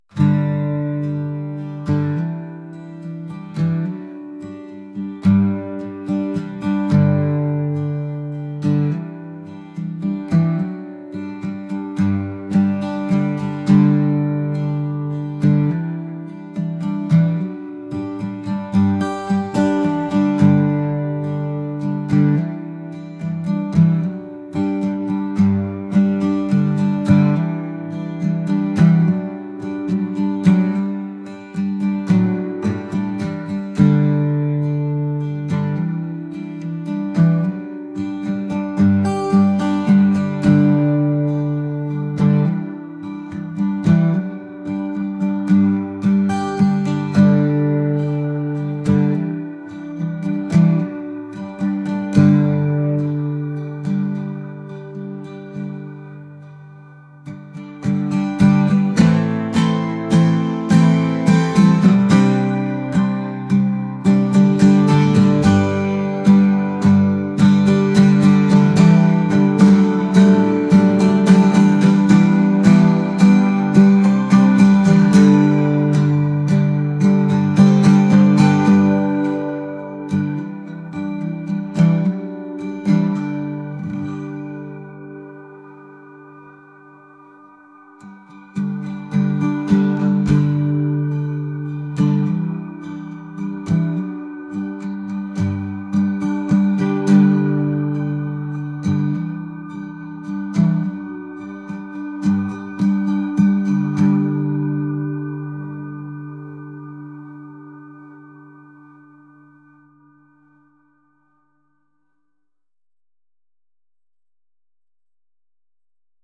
acoustic | indie